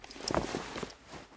looting_2.ogg